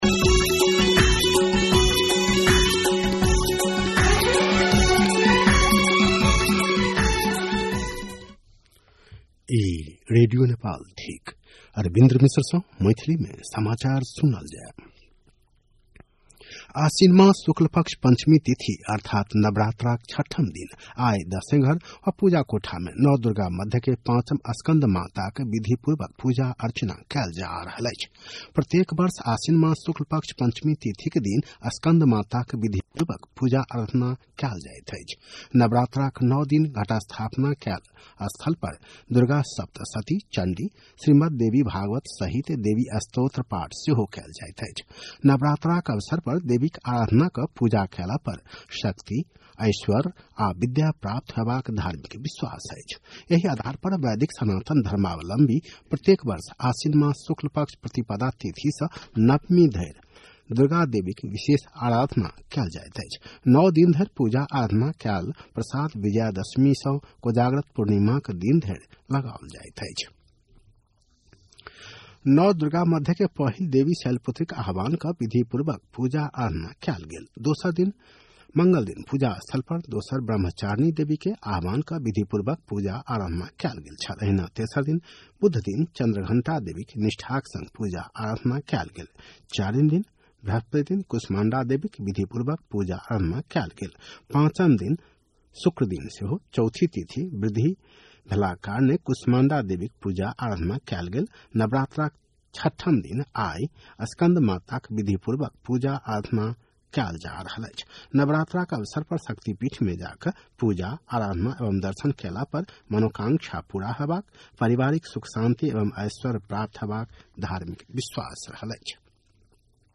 मैथिली भाषामा समाचार : ११ असोज , २०८२
6.-pm-maithali-news-1-3.mp3